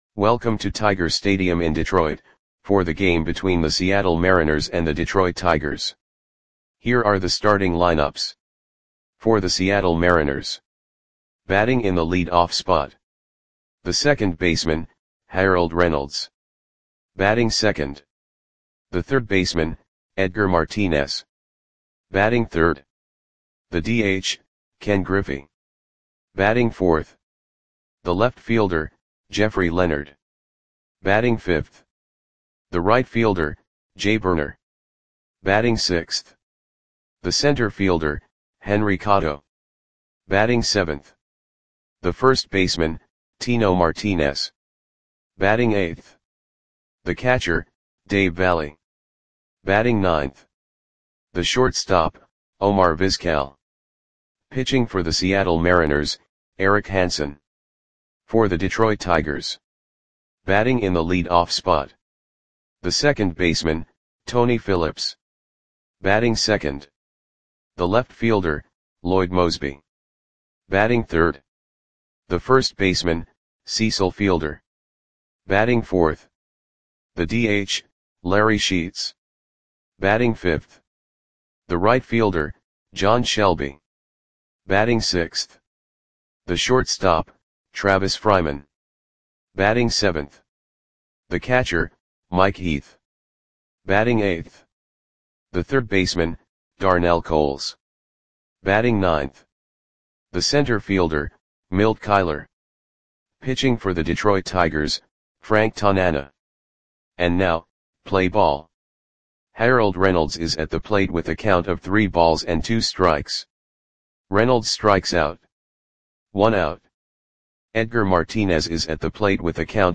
Click the button below to listen to the audio play-by-play.
Mariners 8 @ Tigers 4 Tiger StadiumSeptember 26, 1990 (No Comments)